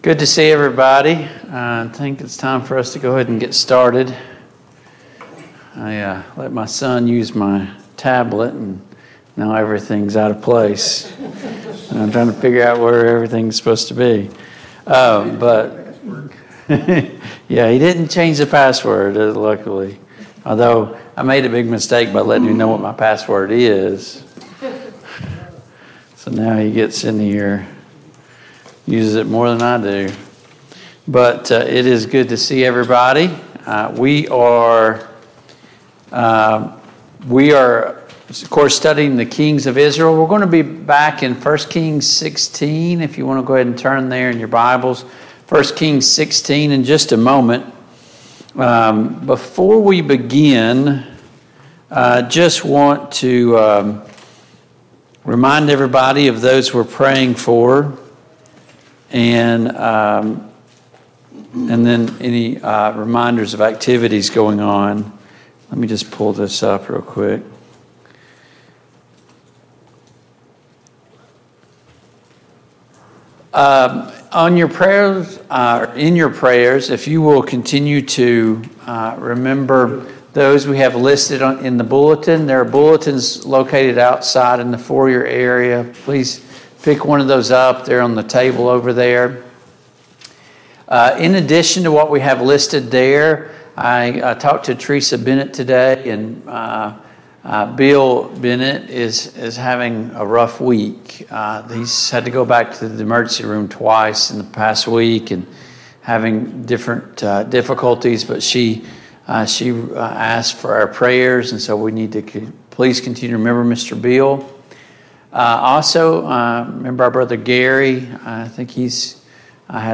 The Kings of Israel Passage: 1 Kings 16 Service Type: Mid-Week Bible Study Download Files Notes Topics